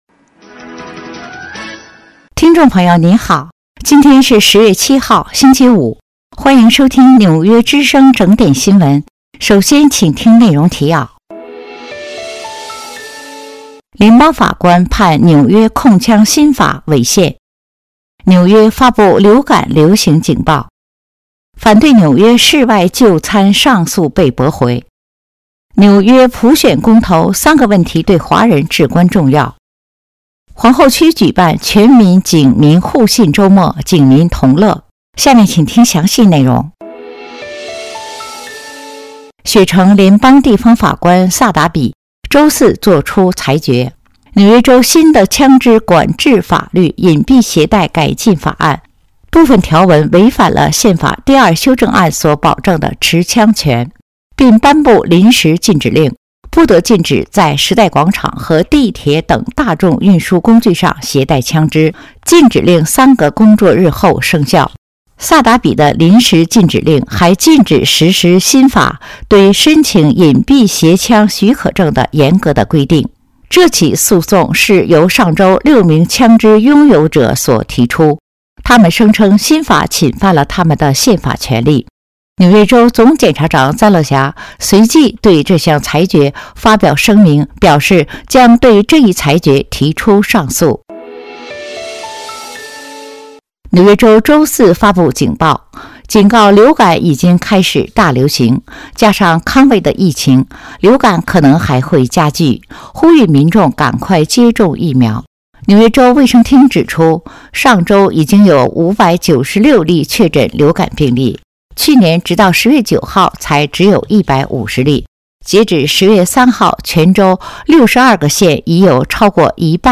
10月7号(星期五)纽约整点新闻